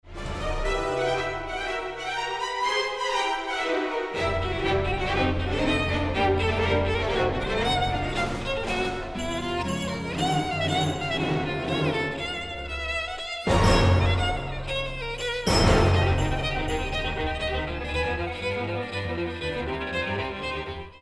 Genre: Classical / Halloween
for Solo Viola and Orchestra
Solo Viola and Solo Violin played and recorded by
Virtual Orchestra produced